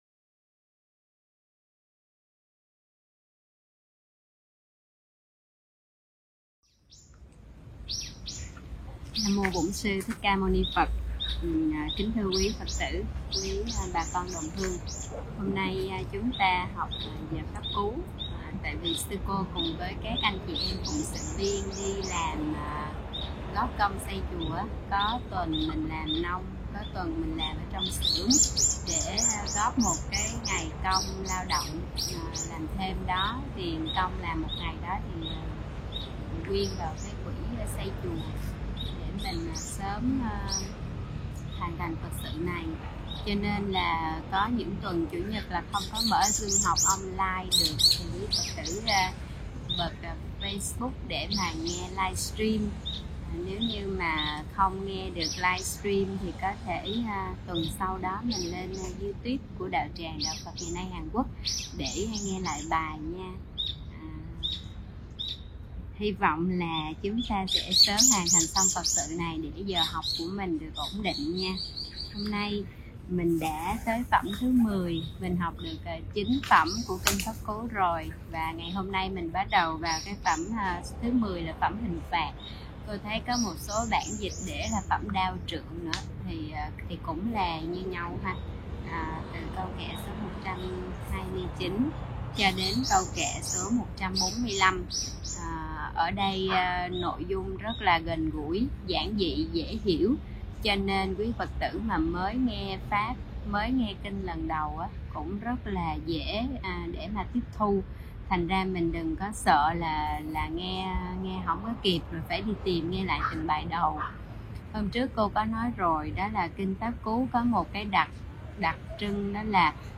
Mời quý phật tử nghe mp3 thuyết pháp Đừng làm tổn thương nhau